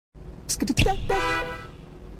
2k green sound skidipi pat pat Meme Sound Effect